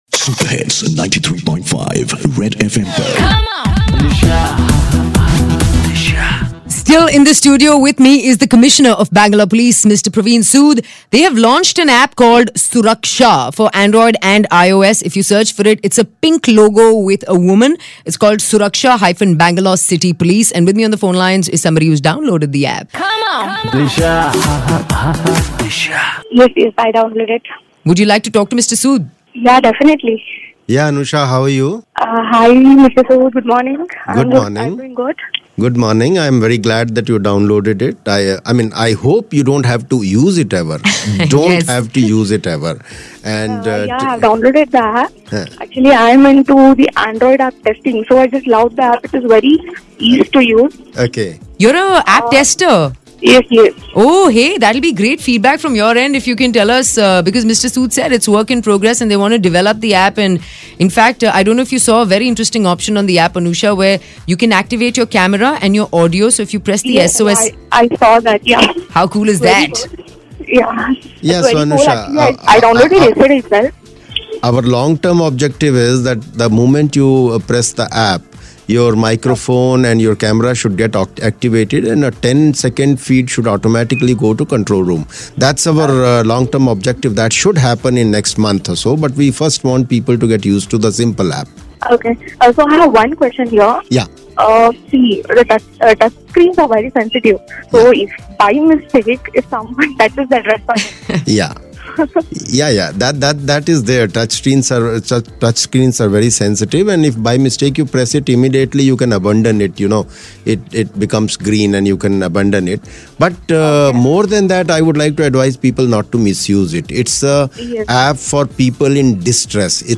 App tester lady calls to Commisonerand takes responsibility to check the app